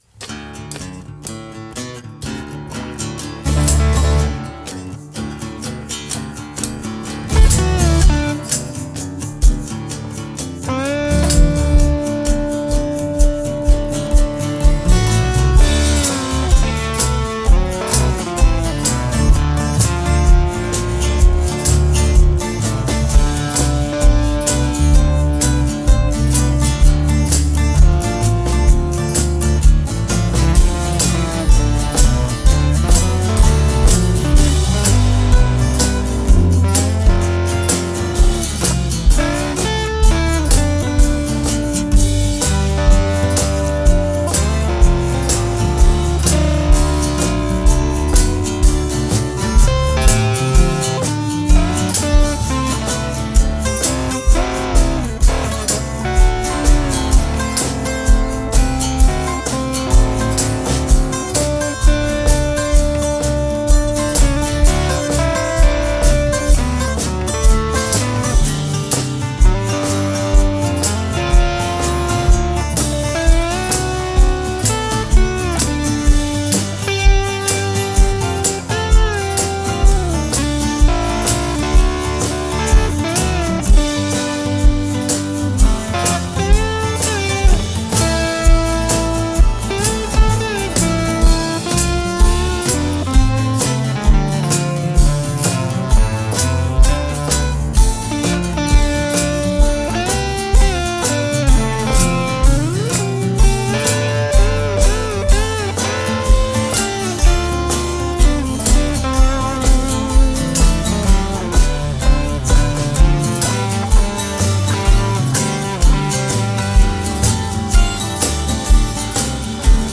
solo overdubbed multitrack recordings